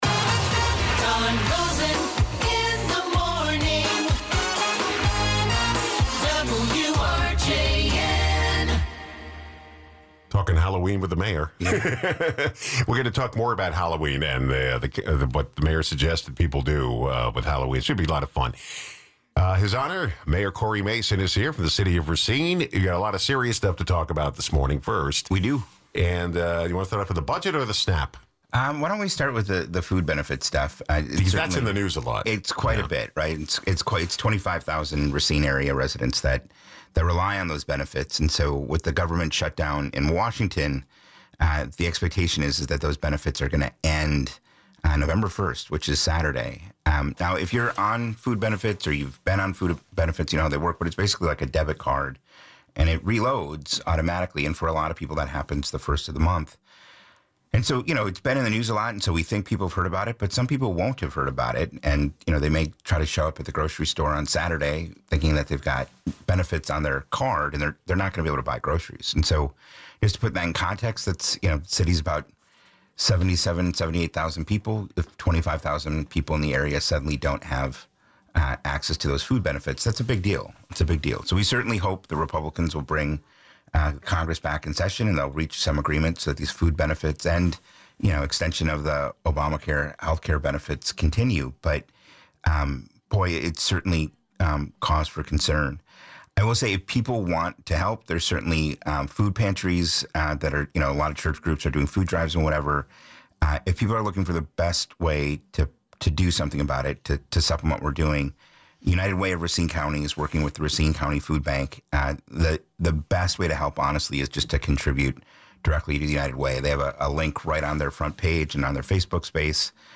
Mayor Cory Mason discusses the possible food emergency for people using the SNAP program and other issue in Racine.
Racine’s Possible Food Emergency Guests: Cory Mason